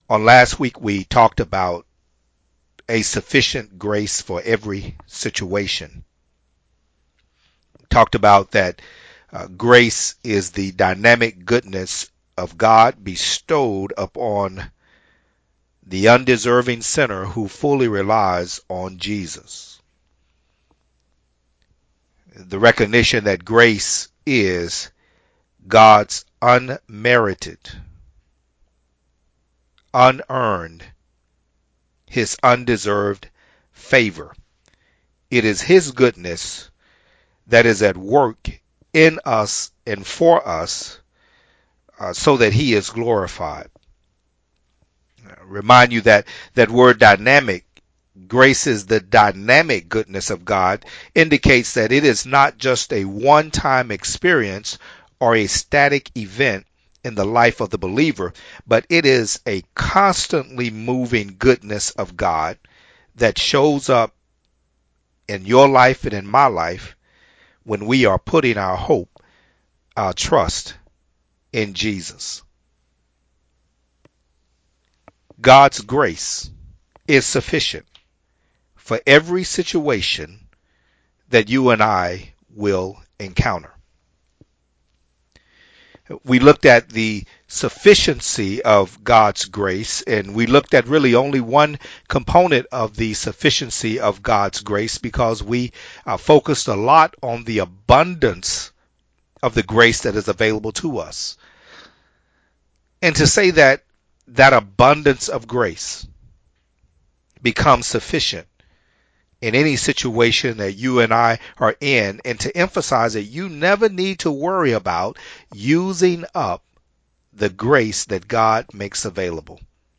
Sermons | New Joy Fellowship Ministry